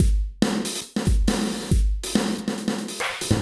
E Kit 28.wav